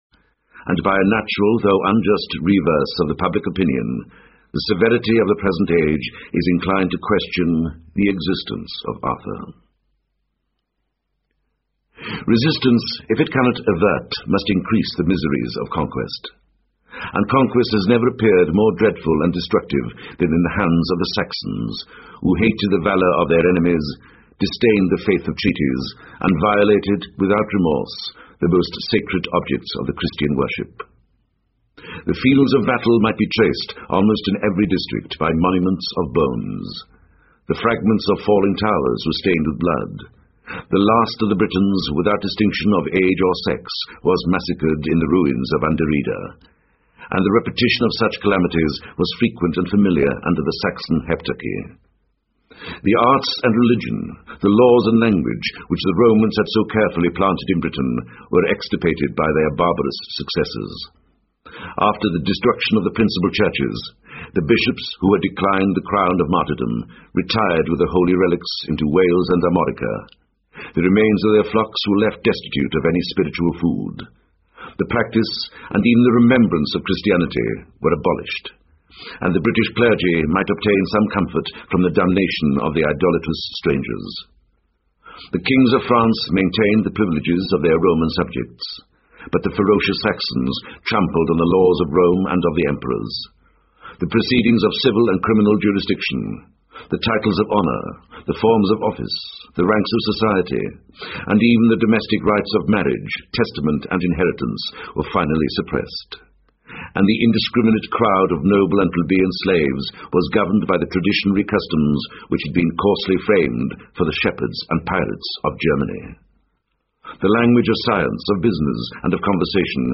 在线英语听力室罗马帝国衰亡史第二部分：05的听力文件下载,有声畅销书：罗马帝国衰亡史-在线英语听力室